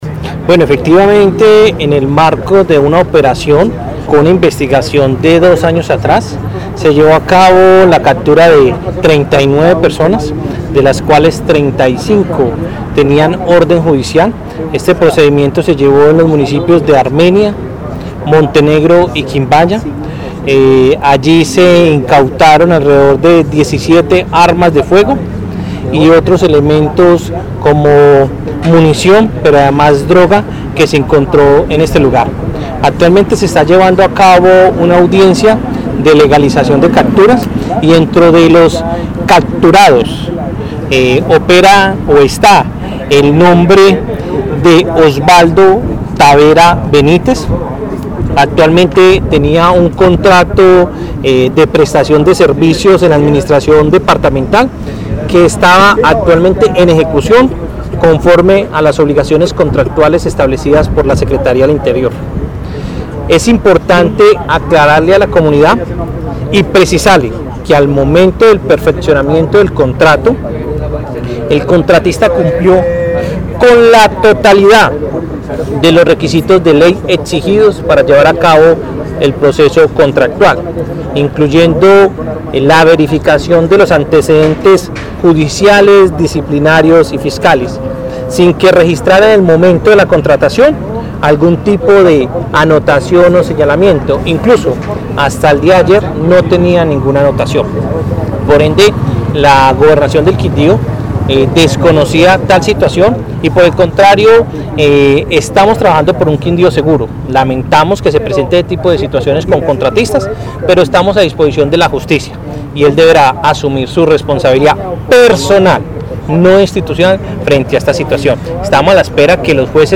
Audio de Jaime Andrés Perez Cotrino, secrterario del Interior, Gobernación del Quindío: